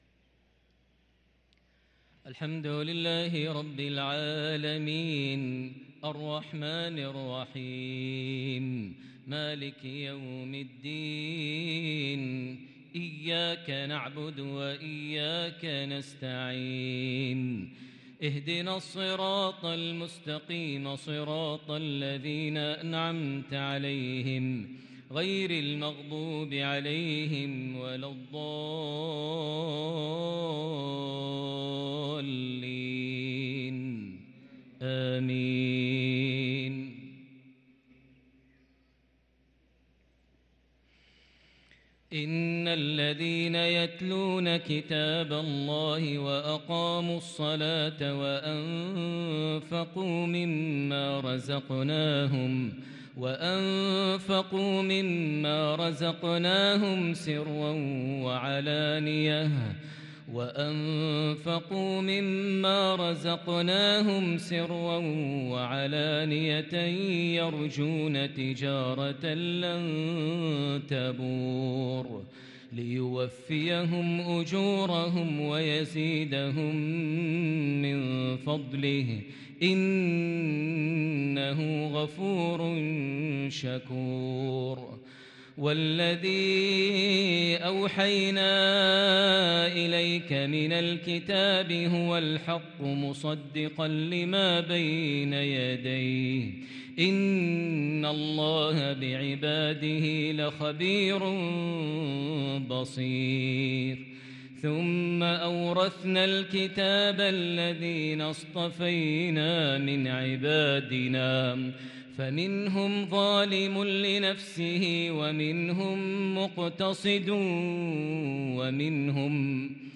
صلاة العشاء للقارئ ماهر المعيقلي 29 ربيع الآخر 1444 هـ
تِلَاوَات الْحَرَمَيْن .